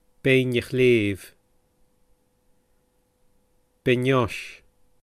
PronunciationGaelic [ˈpeiɲ ə ˈxleːv]